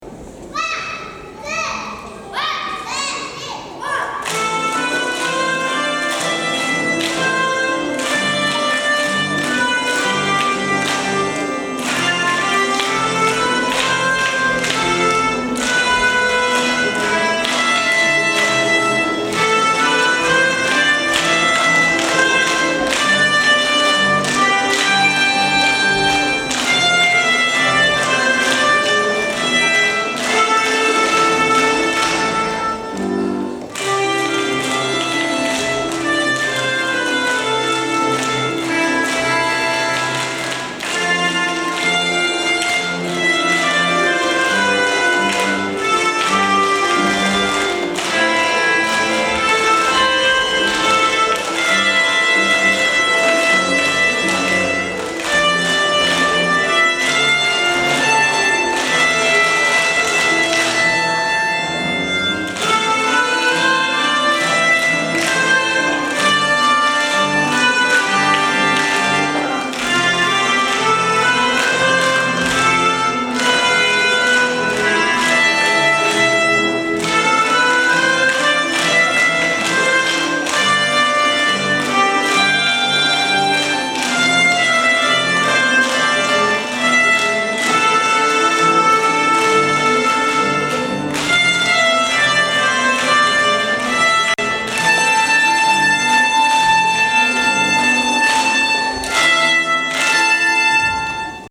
２年生は、鍵盤ハーモニカでの演奏にチャレンジ！
１年生は、自分の手が楽器。
「ワン！ツー！ワン！ツー！スリー！フォー」の元気のよいかけ声で、演奏がスタート！２年生はメロディーを奏で、１年生は２つのリズムを表現しました。